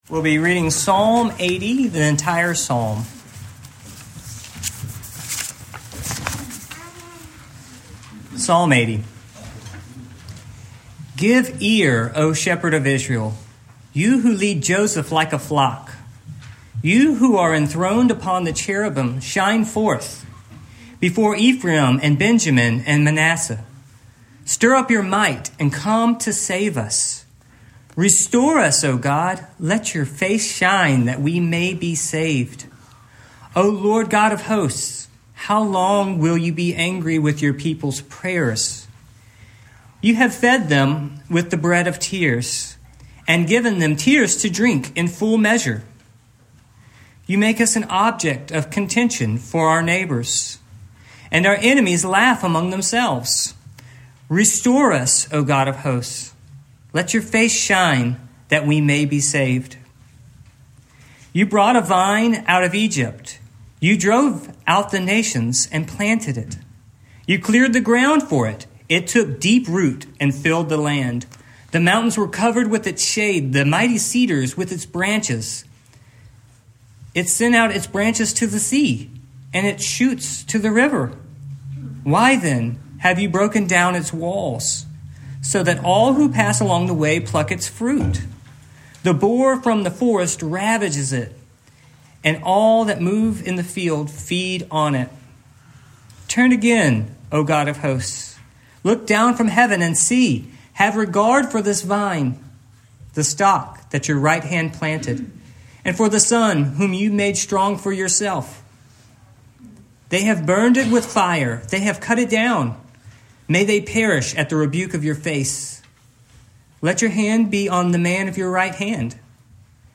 Psalm 80:1-19 Service Type: Morning Main point